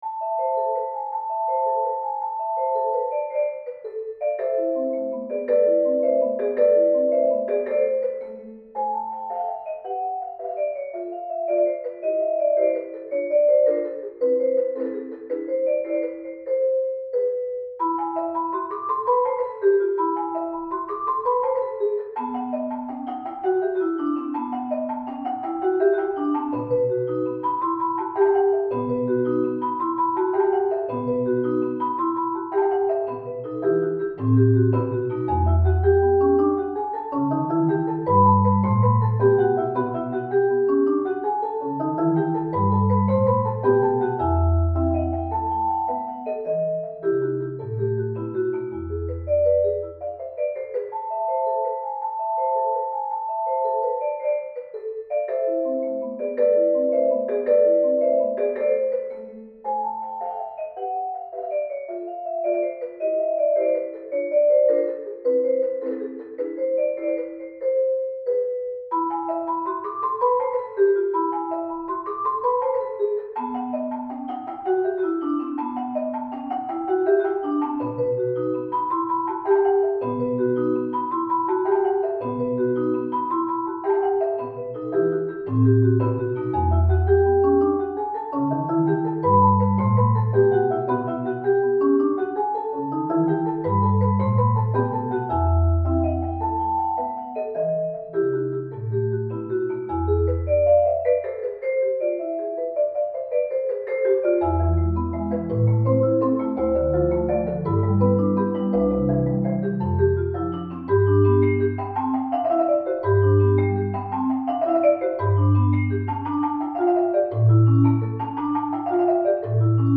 arranged for mallet duet